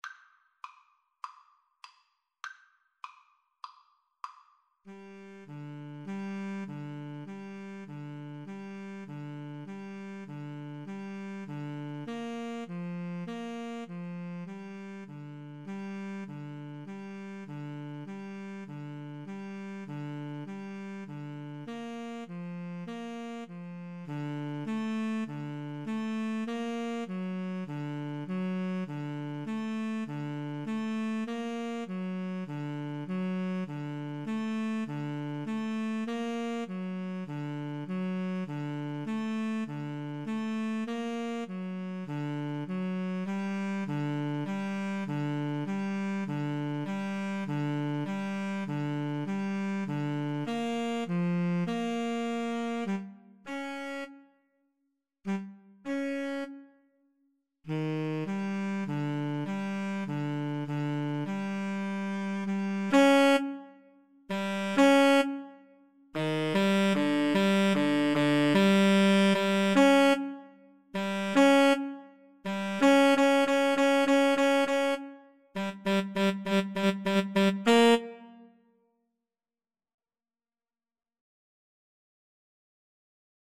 Tenor Sax 1Tenor Sax 2
Classical (View more Classical Tenor Sax Duet Music)